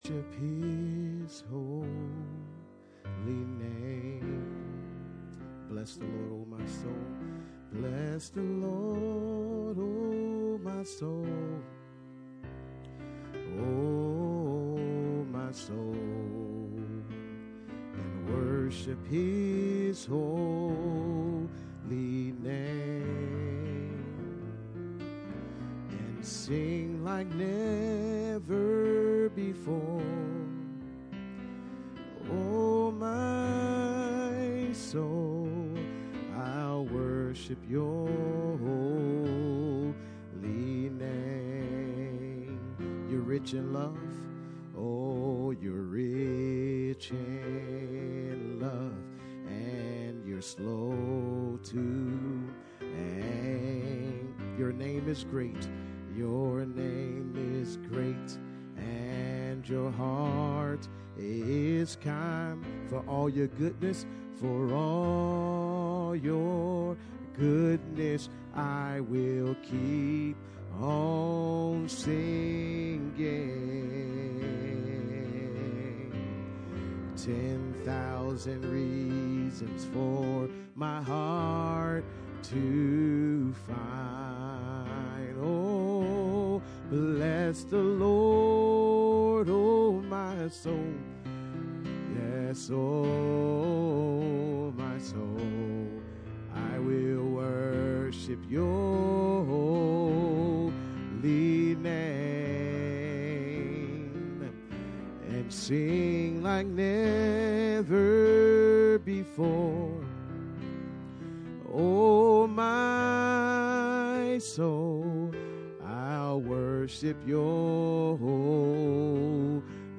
Series: Bible Study
Hebrews 12:1-29 Service Type: Midweek Meeting %todo_render% « Study on Hebrews 12